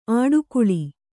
♪ āḍukuḷi